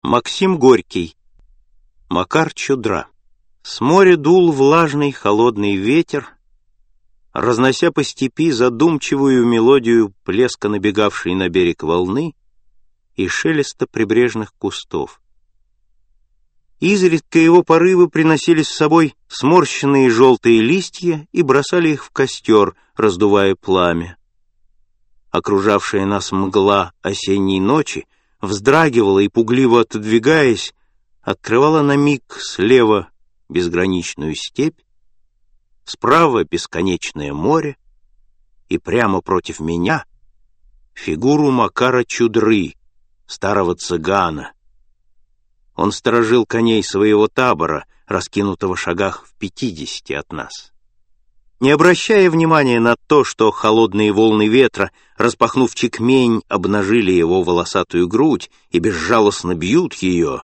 Аудиокнига Рассказы | Библиотека аудиокниг